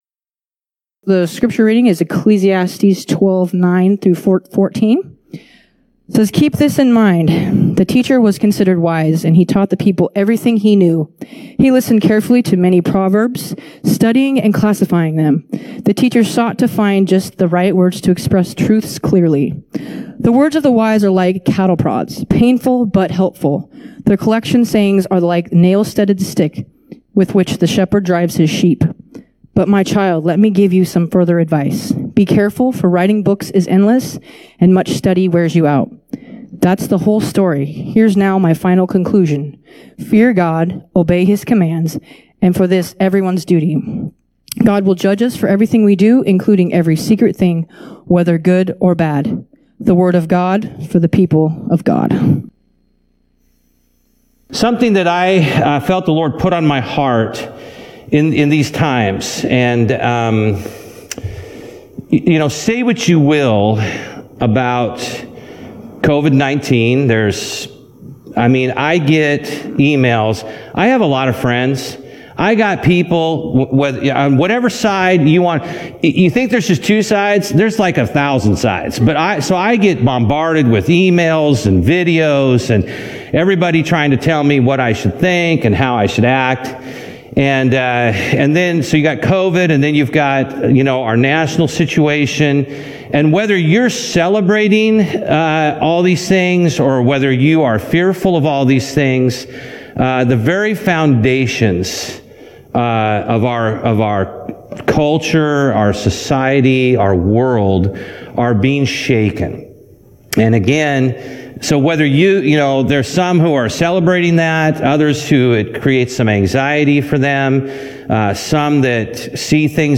Bible Text: Ecclesiastes 12:9-14 | Preacher